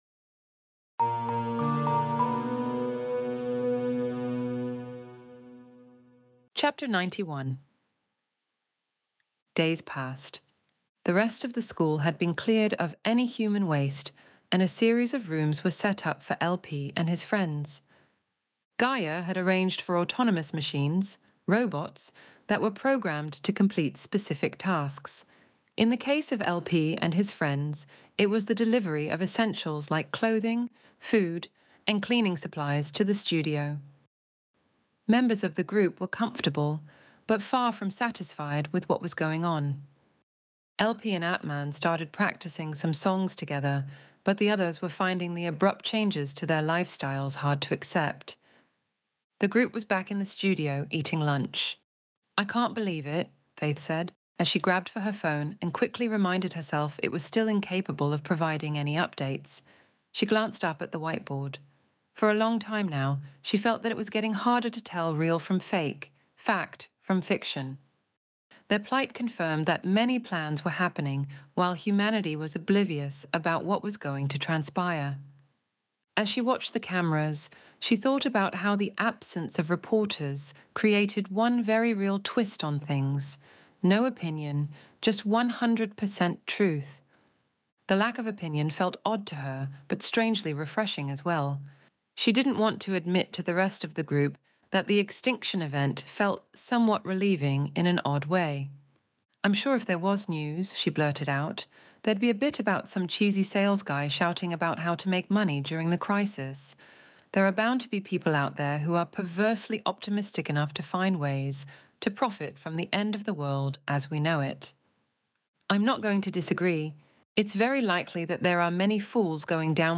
Extinction Event Audiobook Chapter 91